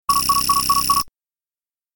دانلود صدای هشدار 30 از ساعد نیوز با لینک مستقیم و کیفیت بالا
جلوه های صوتی